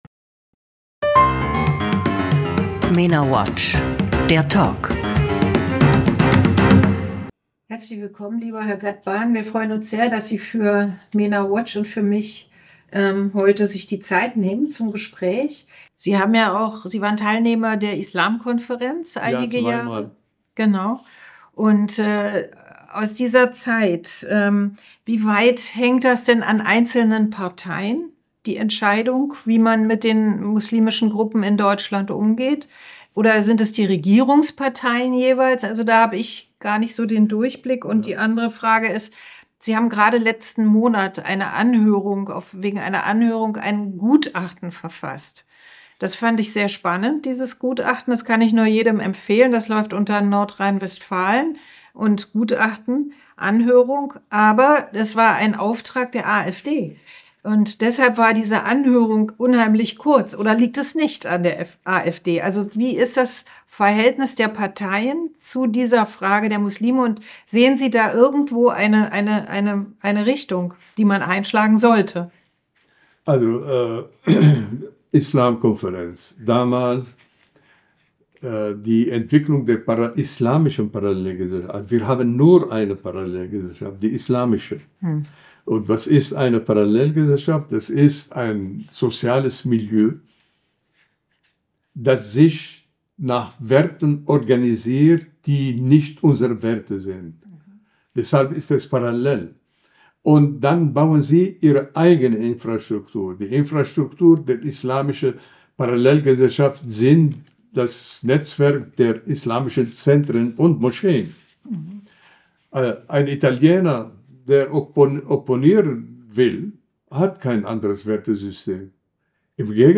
Ein Gespräch in drei Teilen, dessen erste Folge vor zwei Wochen hier und vor einer Woche hier erschien.